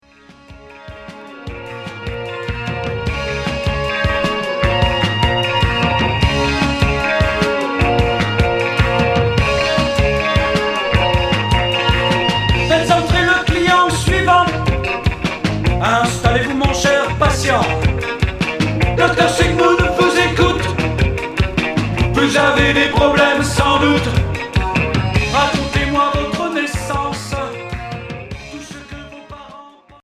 Rock cold wave